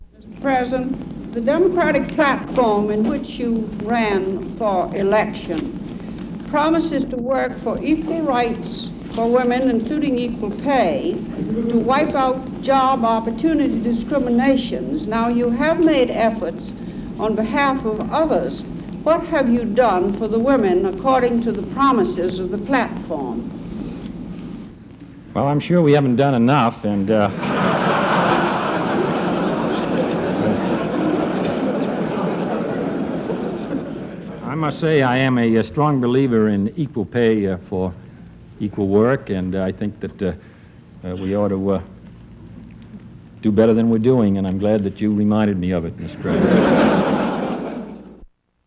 Press conference excerpt
May Craig questions U.S. President John F. Kennedy on what he's done to achieve equal rights for women, including equal pay. Kennedy asserts he believes in equal pay for equal work and acknowledges there is more work to be done to achieve it.